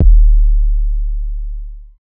Southside 808 (17).wav